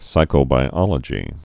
(sīkō-bī-ŏlə-jē)